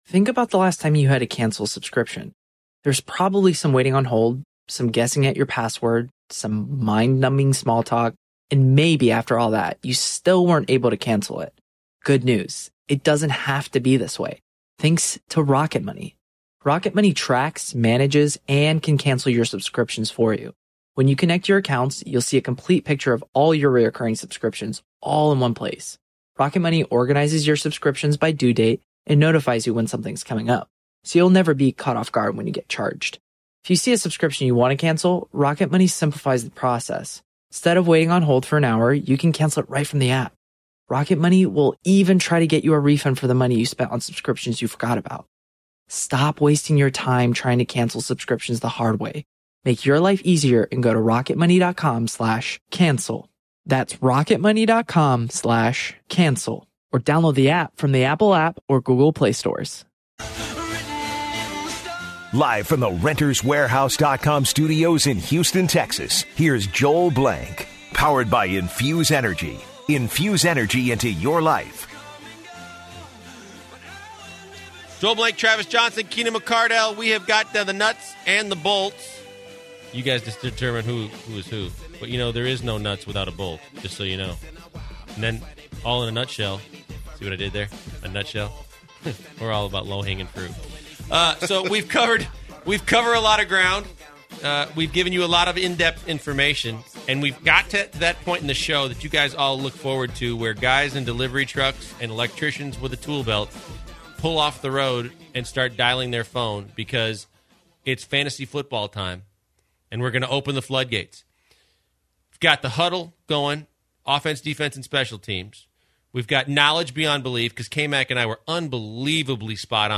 They are joined by a couple of callers who talk about their fantasy football teams.